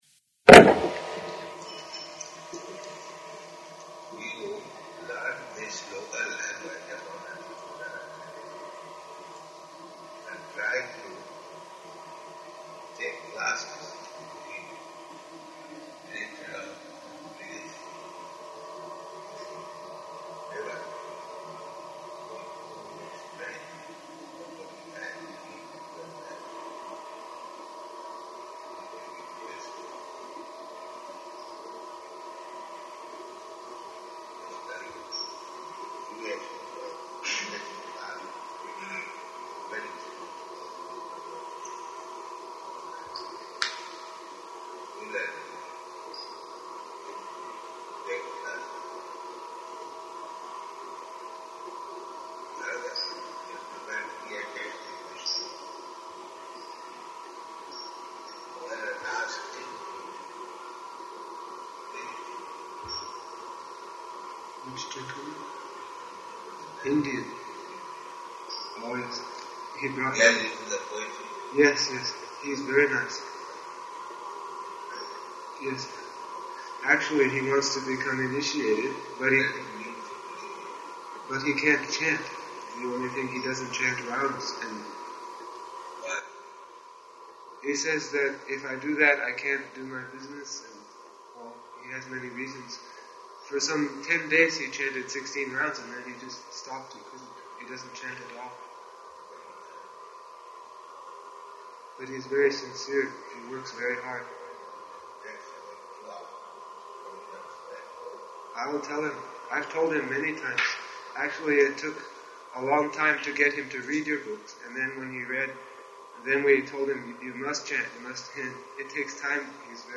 Room Conversation
Type: Conversation
Location: Jakarta
[poor audio---microphone too far away]